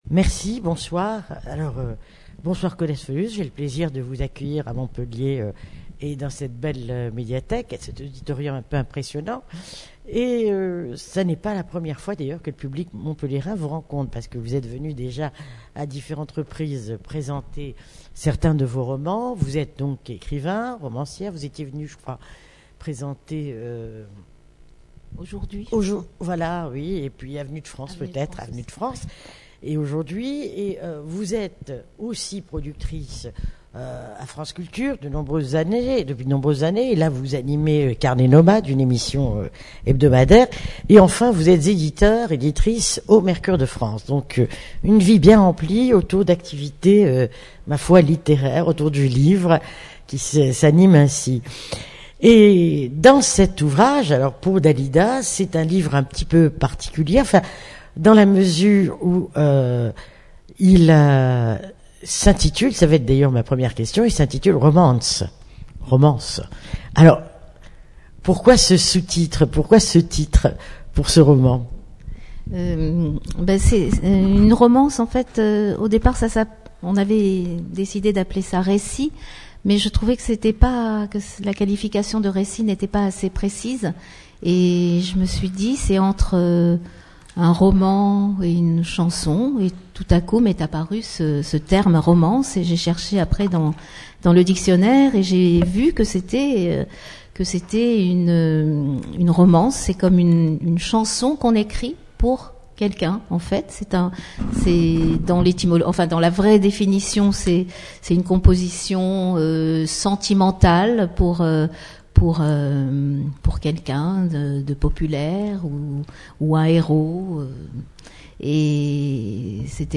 Livres sonores
Rencontre littéraire